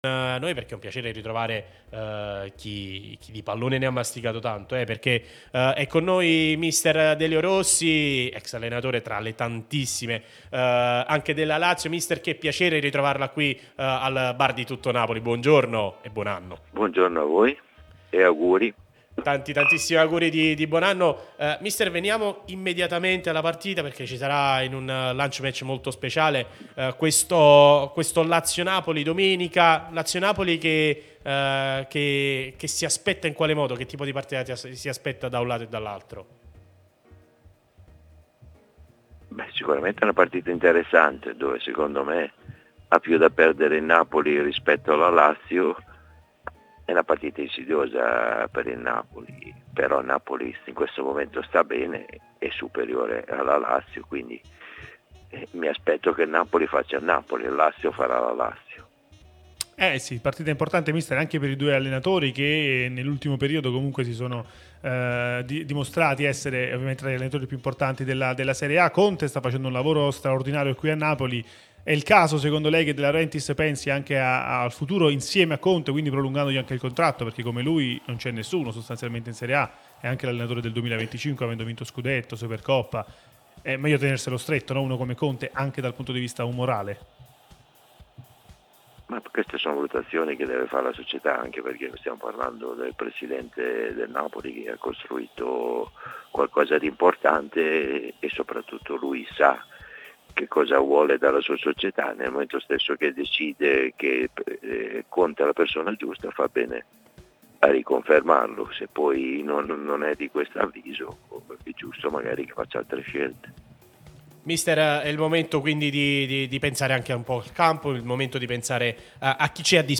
Delio Rossi, allenatore ex Lazio, è intervenuto nel corso de Il Bar di Tuttonapoli, trasmissione sulla nostra Radio Tutto Napoli, prima radio tematica sul Napoli, che puoi ascoltare/vedere qui sul sito, in auto col DAB Campania o sulle app gratuite (qui per Iphone o qui per Android).